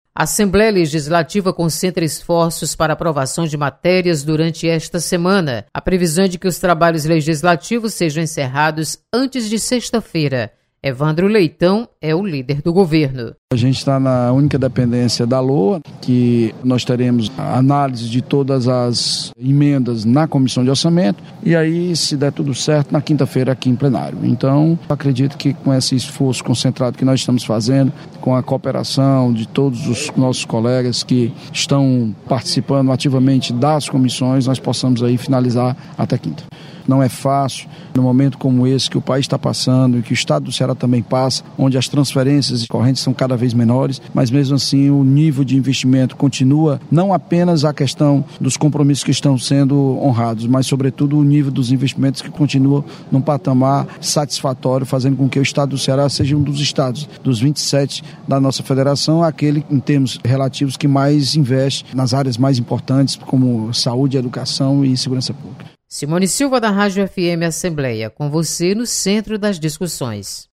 Lei Orçamentária Anual é prioridade da pauta de votações. Repórter